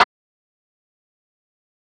Snare (SizzleLife1).wav